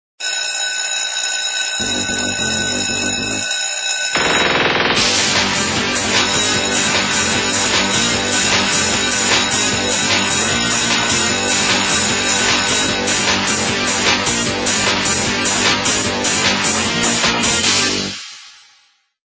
Рингтоны на будильник